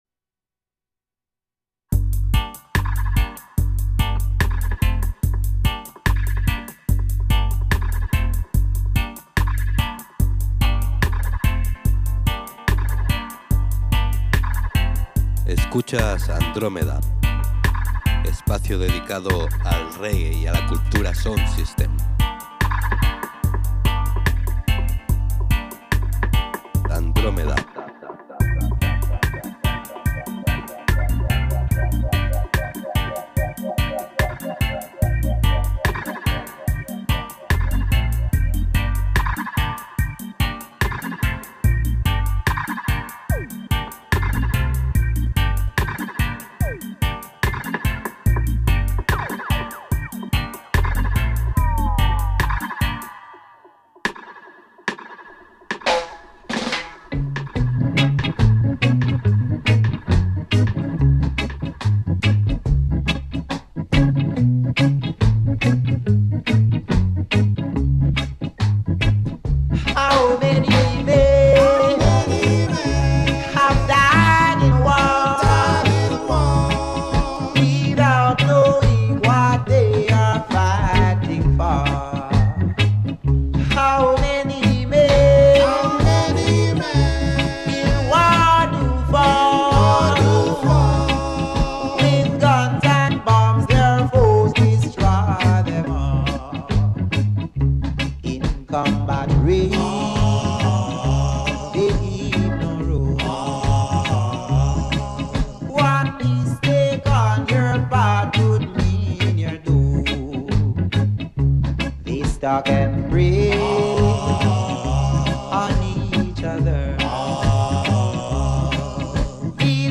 Reggae Music